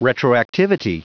Prononciation du mot retroactivity en anglais (fichier audio)